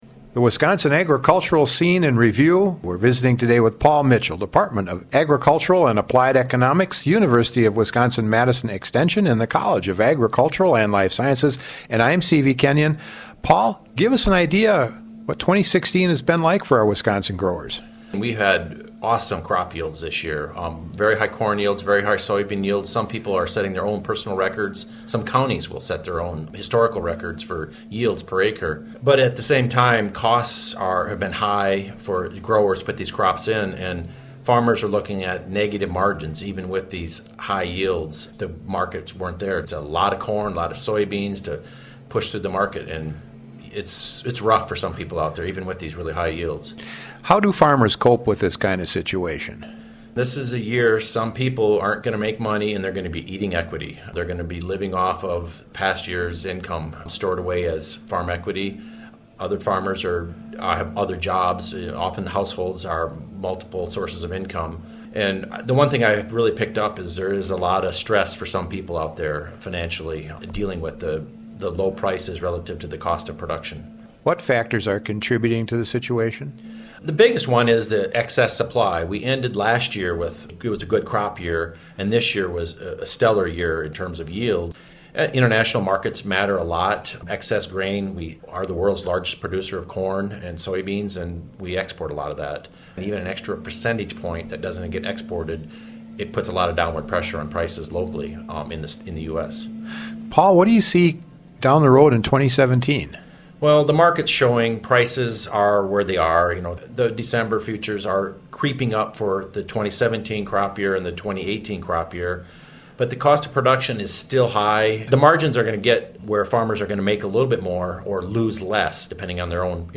The Wisconsin agricultural scene in review, we’re visiting today with